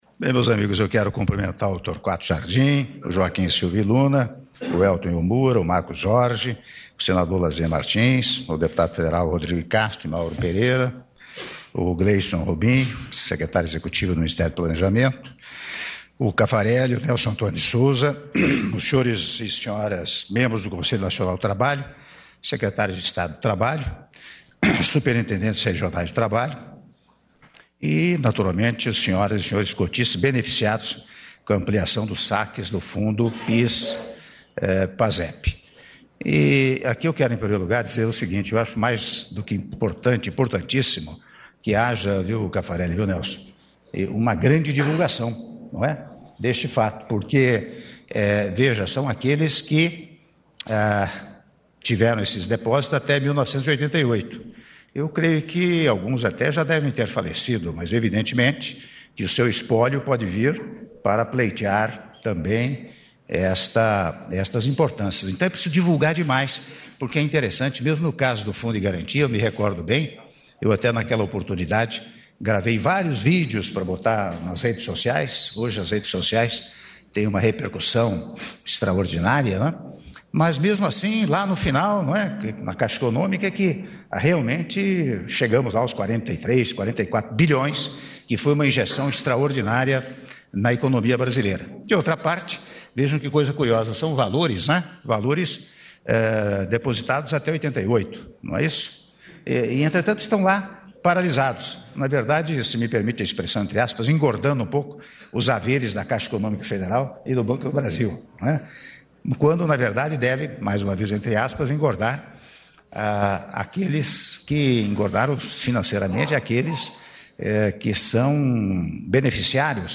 Áudio do discurso do Presidente da República, Michel Temer, durante Cerimônia de Anúncio da Ampliação dos Saques do Fundo PIS/Pasep -Brasília/DF- (04min58s)